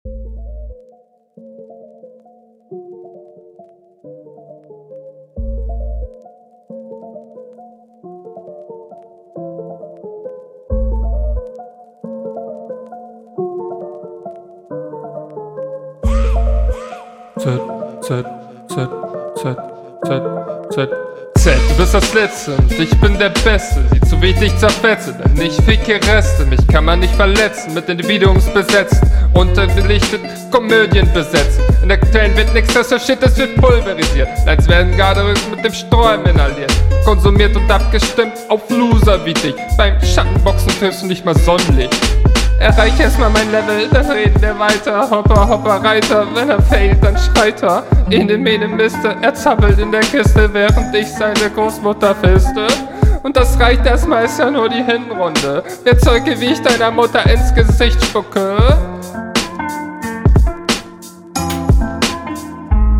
Ich finde du hasst dich auf jeden Fall verbessert, die Betonungen klingen zwar manchmal merkwürdig …